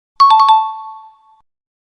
Скачать звук галочки (правильно)
Здесь вы можете слушать онлайн и скачать звук поставленной галочки для монтажа видео.